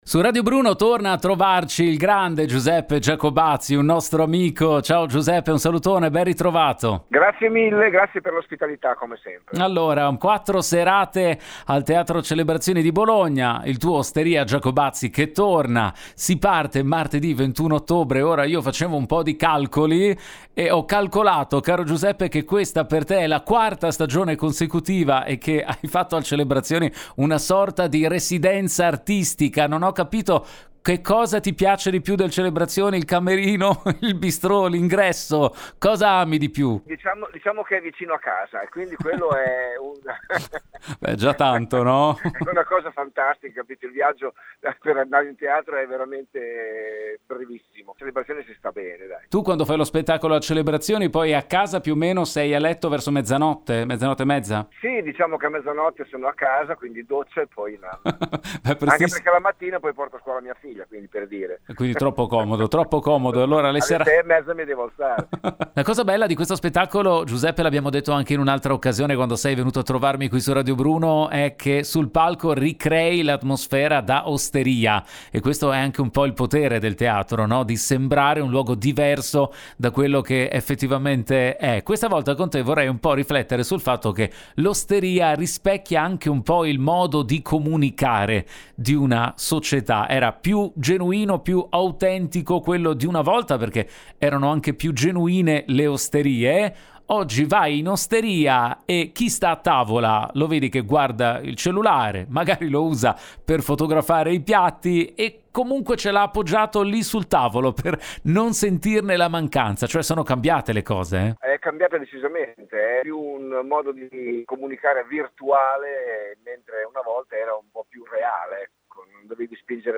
Sentiamo Giacobazzi, raggiunto al telefono